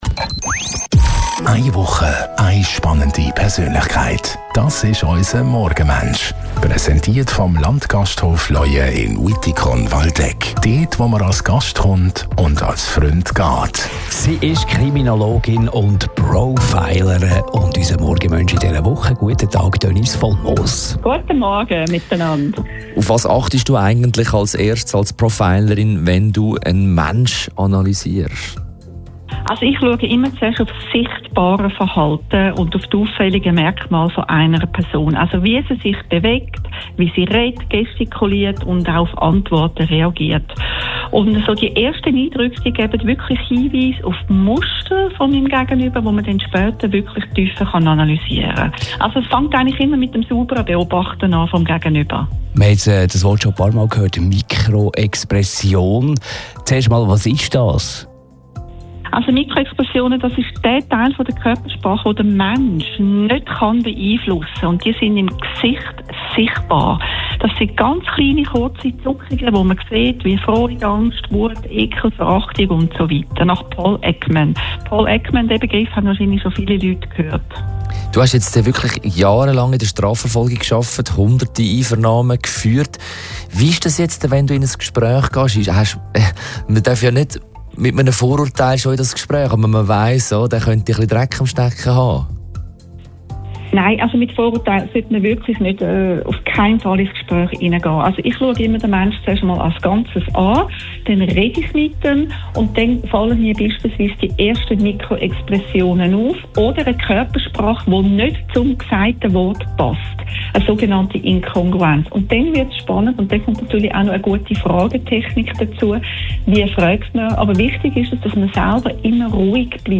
telefonieren jeden Morgen von Montag bis Freitag nach halb 8 Uhr mit einer interessanten Persönlichkeit.